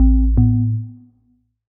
Melodic Power On 8.wav